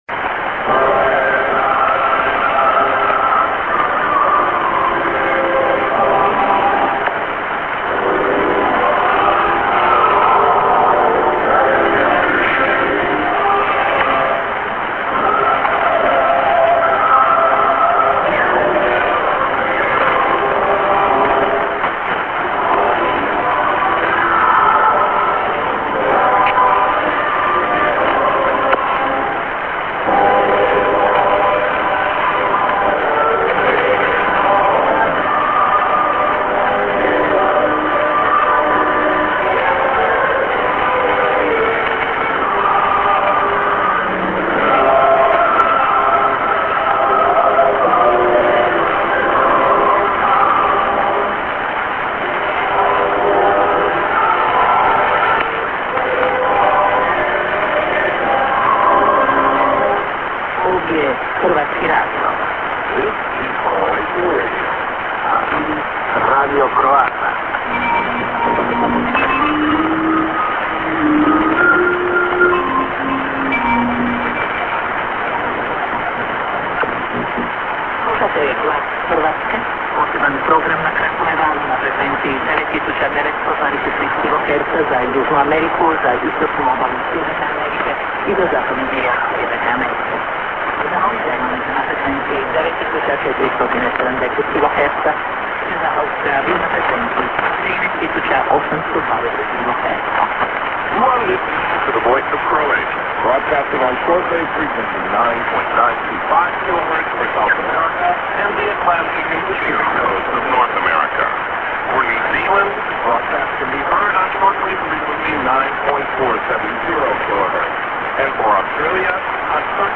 St. music->ID(women+man)->ST->ID+SKJ(women&man:mult.lang.)->ST-> via Julich for NZ